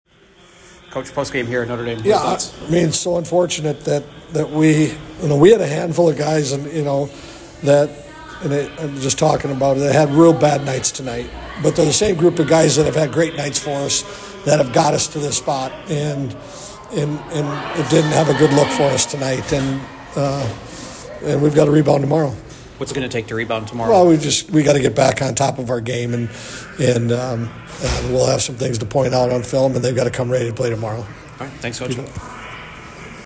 Post game audio